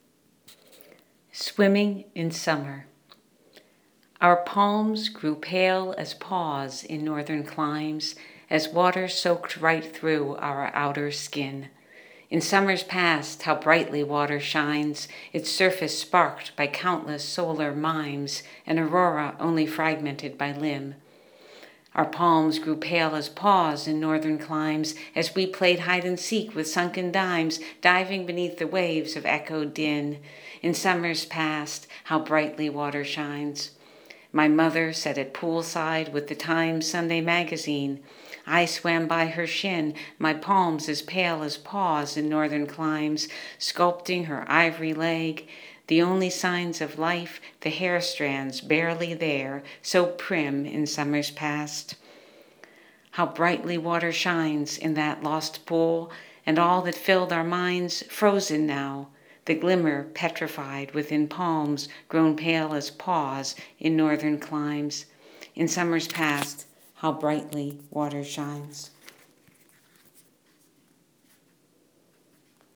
(It’s a bit slow at the beginning but speeds up very quickly.)   More importantly, it illustrates  punctuation at work!